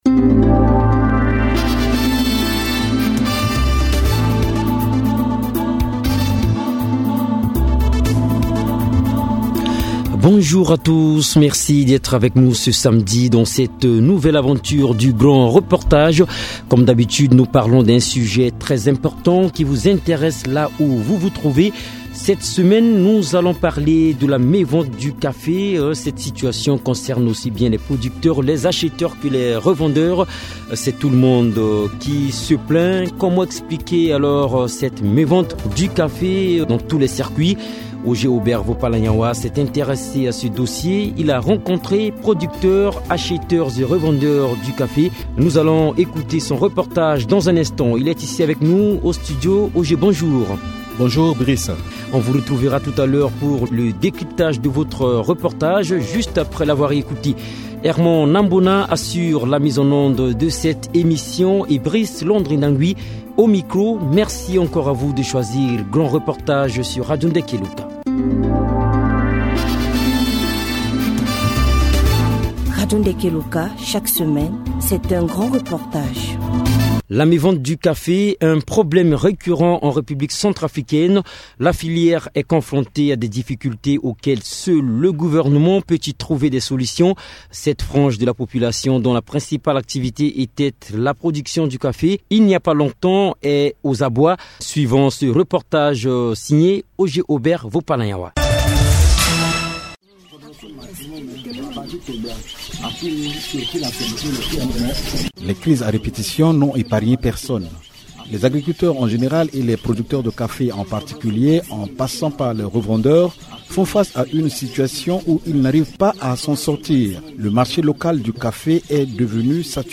grand Reportage